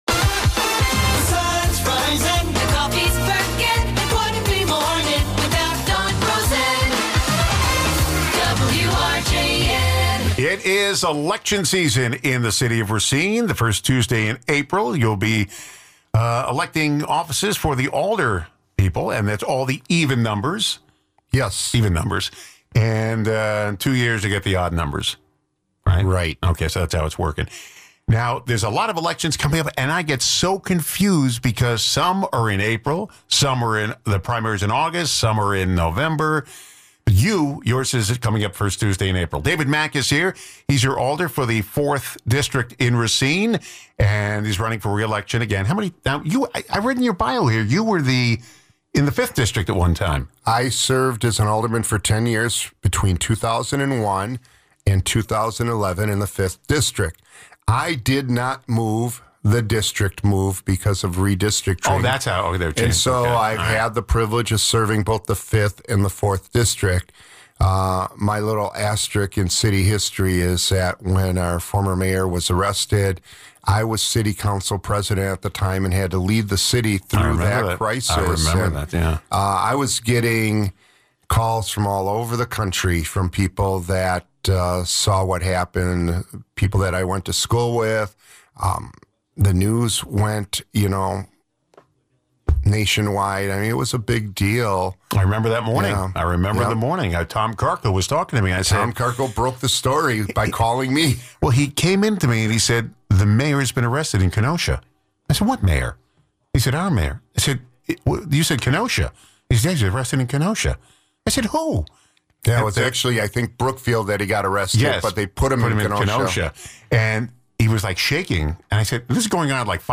Guests: David Maack